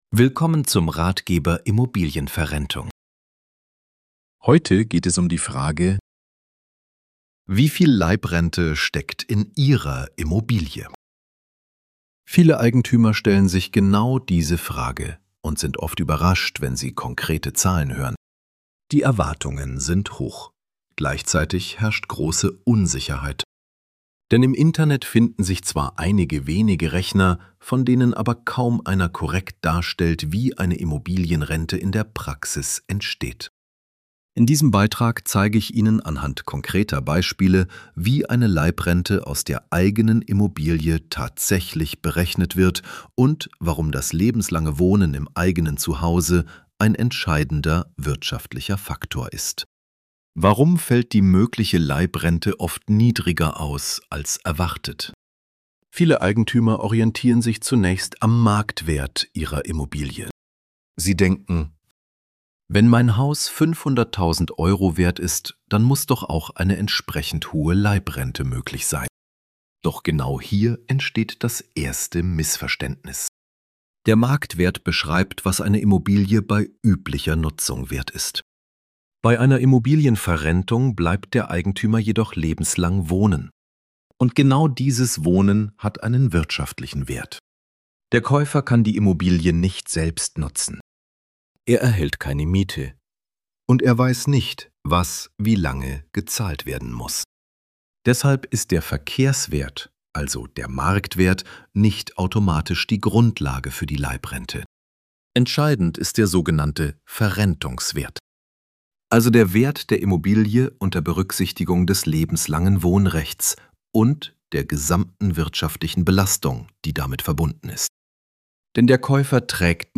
Wenn Sie den Artikel lieber anhören möchten, finden Sie hier die gesprochene Fassung des Beitrags. Der Text wurde für das Audio-Format sprachlich angepasst.